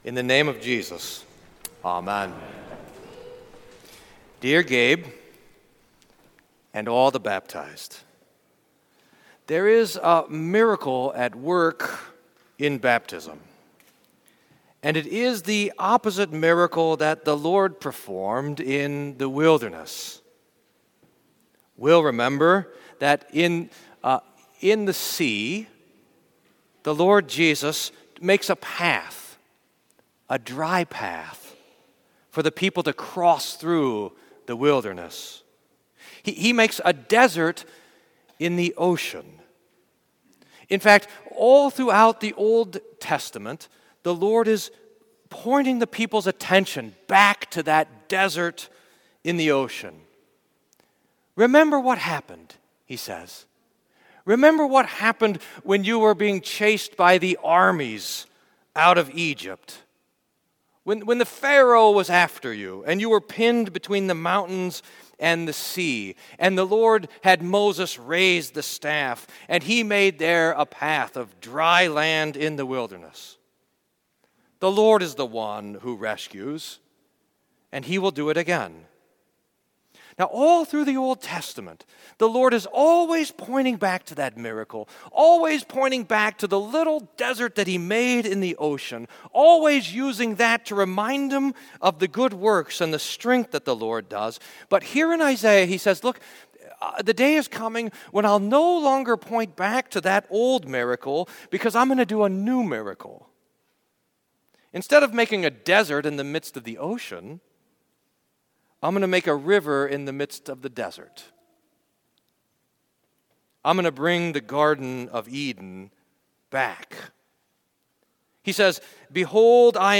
Sermon for Fifth Sunday in Lent